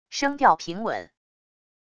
声调平稳wav音频